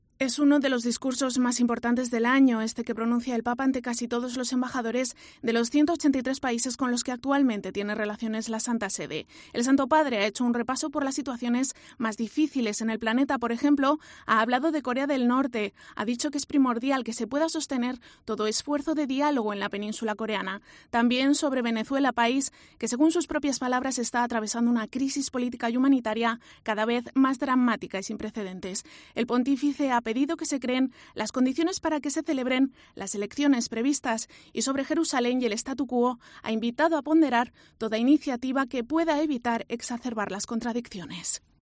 El Papa Francisco da su tradicional discurso ante el cuerpo diplomático acreditado en la Santa Sede. Ha repasado las situaciones de conflicto que se viven en el mundo, fruto de sus viajes, entre los que destacan: Colombia, Venezuela, Egipto y Portugal.
El Papa da un discurso ante la Santa Sede